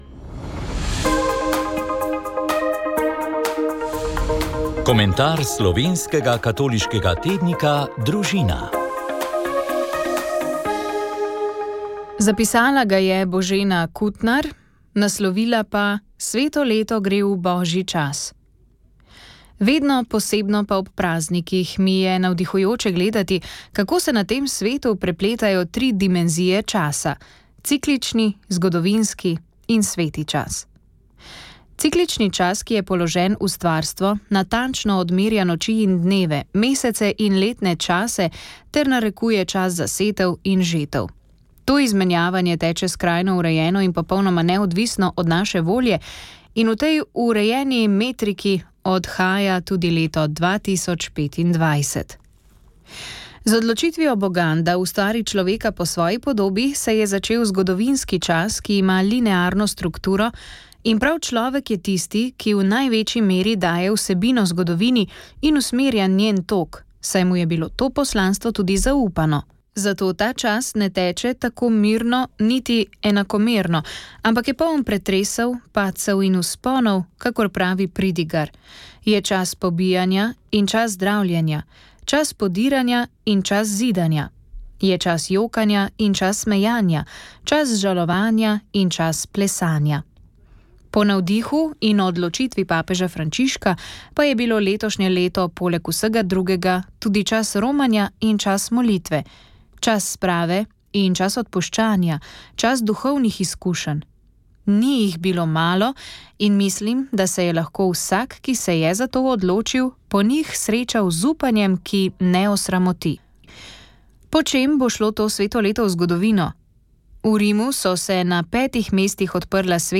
Rožni venec
Molitev je vodil nadškof Alojz Uran.